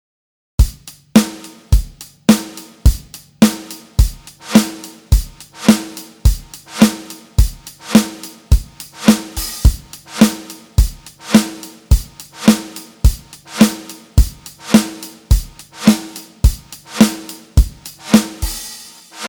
次にLFOへの影響を与えないままハイパスのFREQをあげてみます。
音が軽くなってパーカッシブな感じになりました。